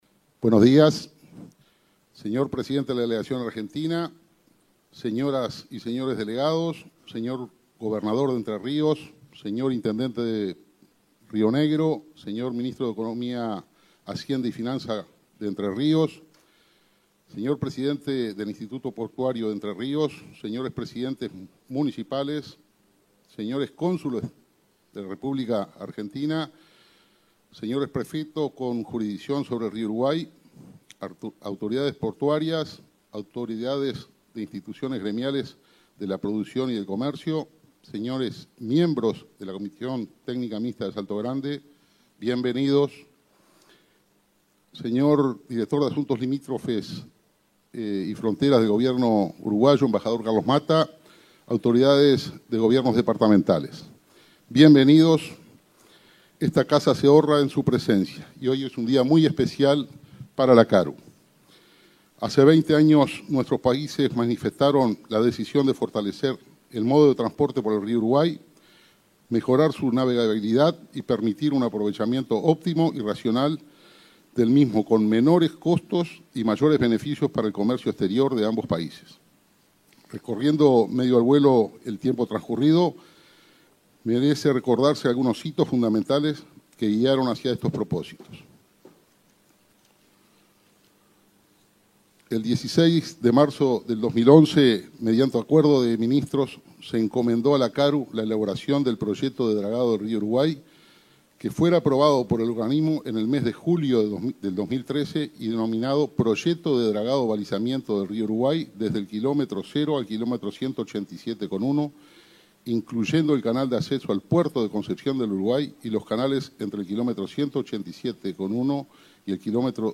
Palabras del presidente de la Comisión Administradora del Río Uruguay, Mario Ayala